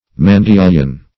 Mandilion \Man*dil"ion\, n.